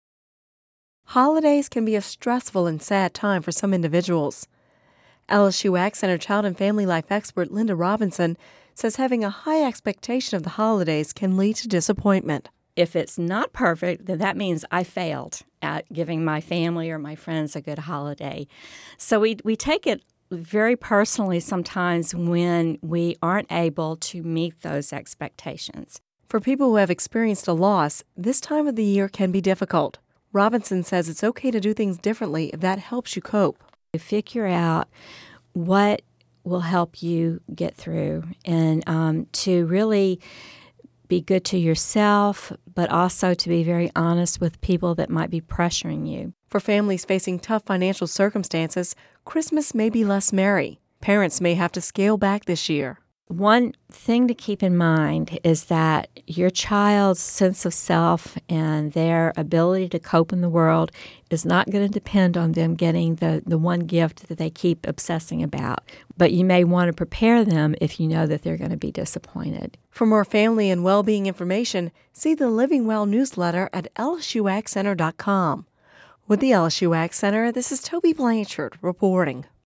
(Radio News 12/20/10) Holidays can be a stressful and sad time for some individuals.